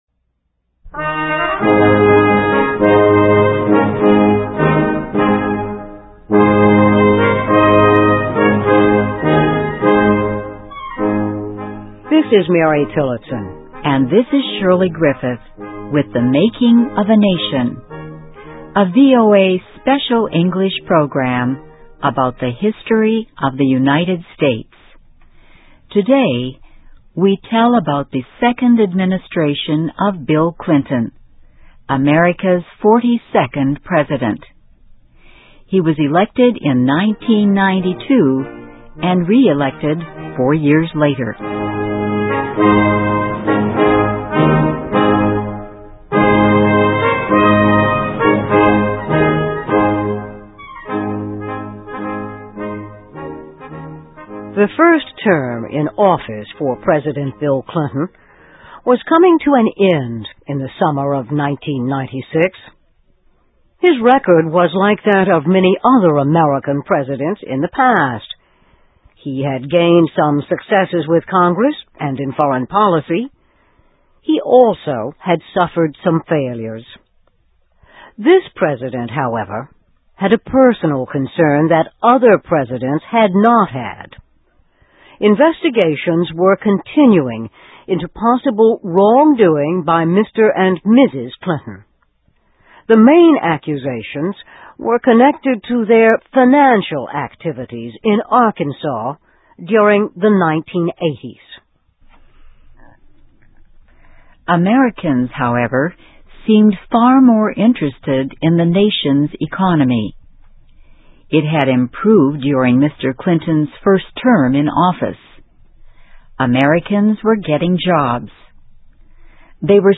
American History: Bill Clinton Wins Re-election in 1996 (VOA Special English 2007-08-08)
Listen and Read Along - Text with Audio - For ESL Students - For Learning English